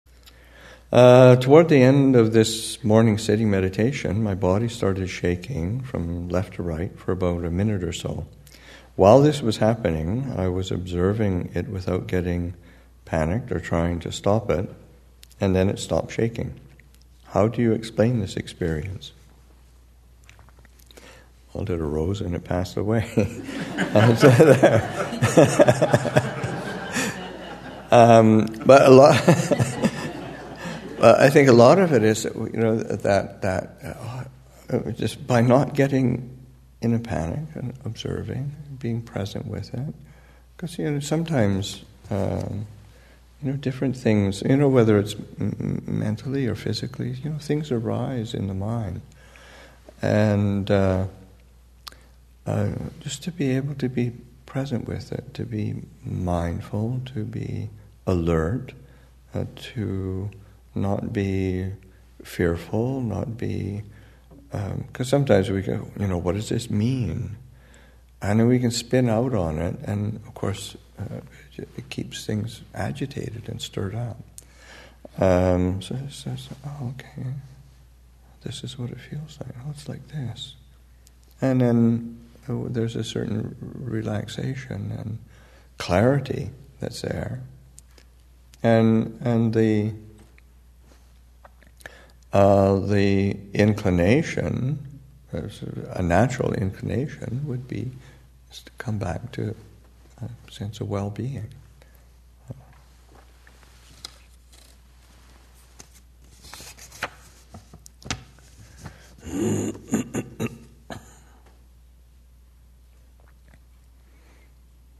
2015 Thanksgiving Monastic Retreat, Session 6 – Nov. 26, 2015